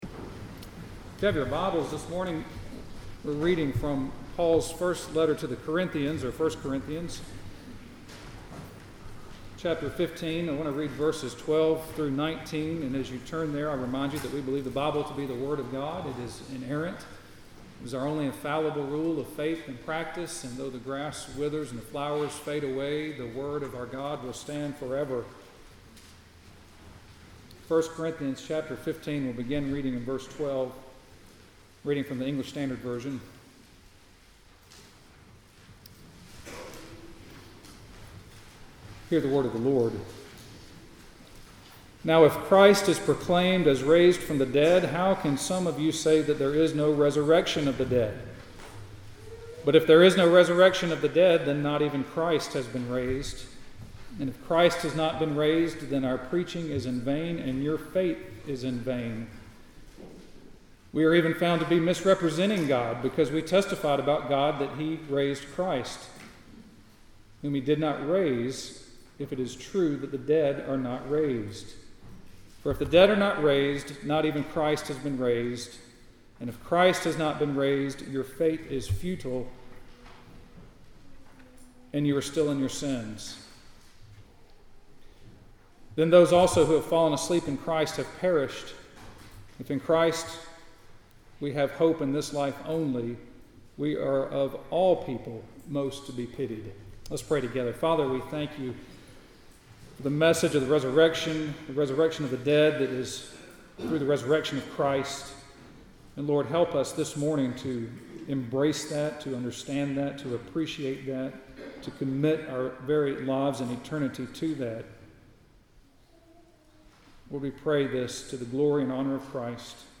Sermons What Is Saving Faith?